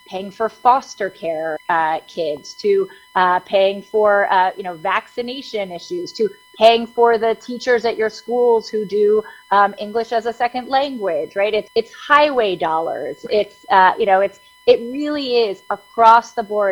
Recently, Maryland Congresswoman Sarah Elfreth held a town hall where she spoke with state Comptroller Brooke Lierman about some of the resource cuts…